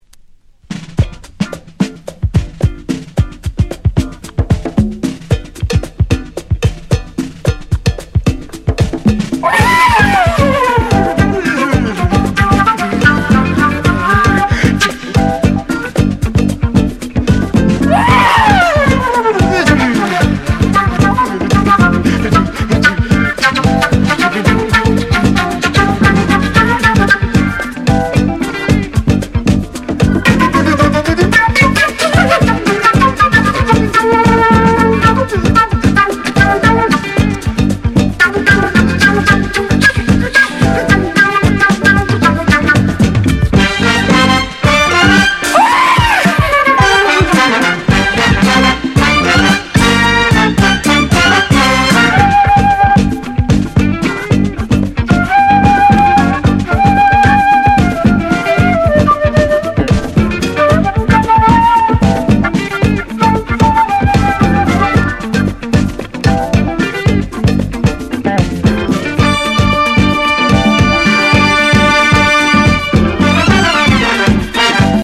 プログレッシヴ・フルート！
• 特記事項: STEREO